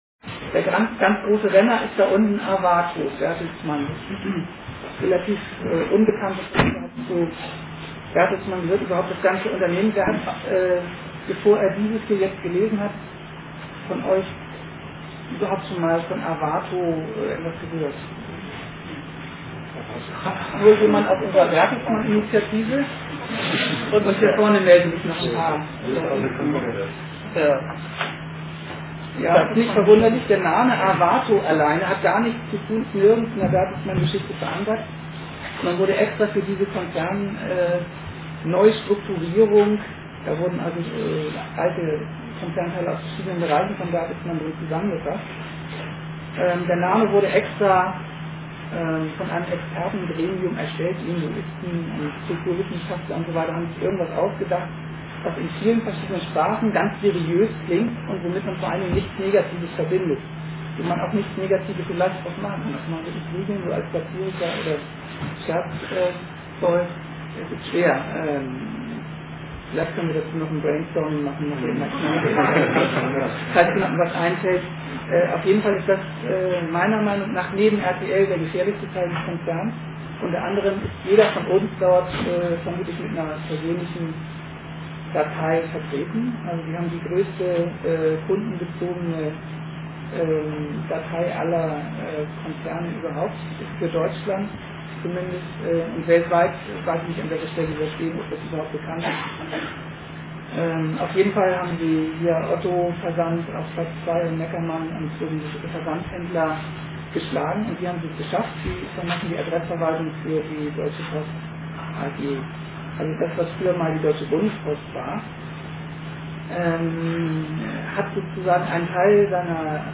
Attac-AG "Privatisierung Nein!", GEW BV-Ffm, ver.di FB 5 (Ffm), Jusos Ffm: hatten ins Gewerkschaftshaus eingeladen.
Über 80 Besucher erlebten einen interessanten Abend.